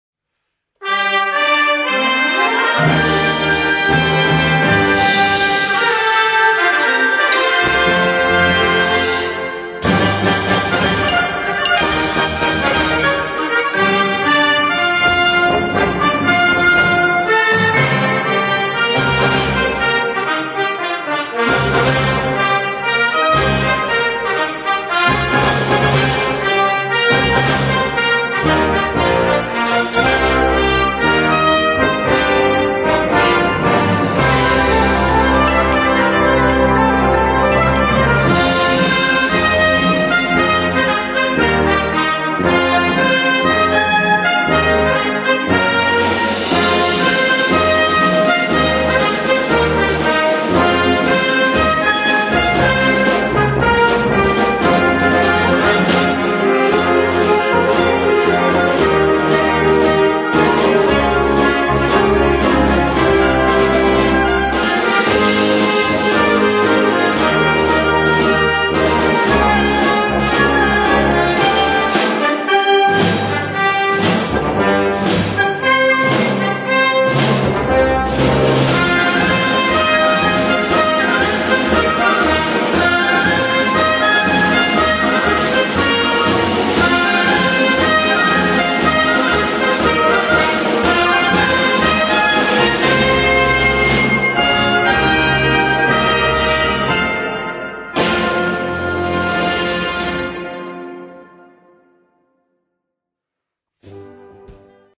Partitions pour orchestre d'harmonie et fanfare.